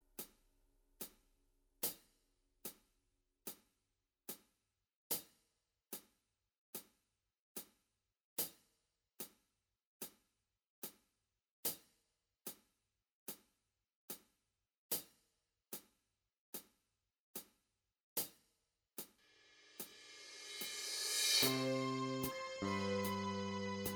Minus All Guitars Rock 4:58 Buy £1.50